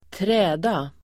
Uttal: [²tr'ä:da]